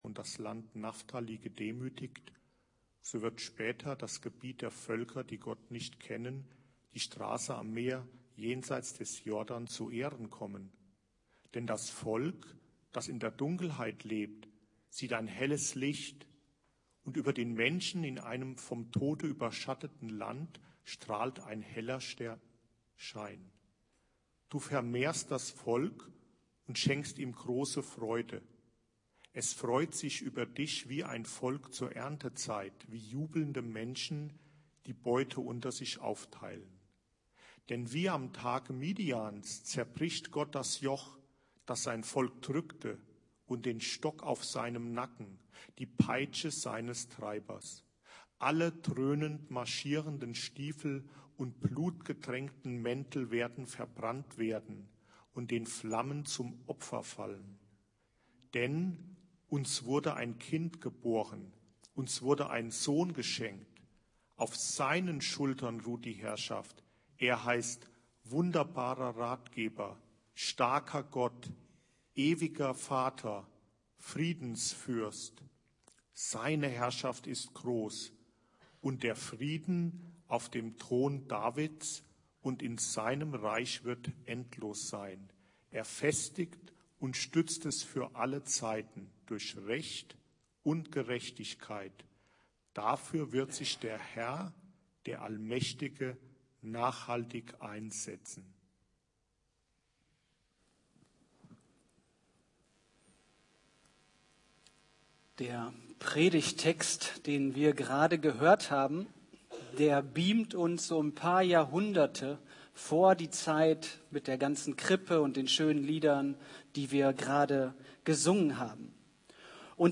Predigt Mit dem Laden des Videos akzeptieren Sie die Datenschutzerklärung von YouTube.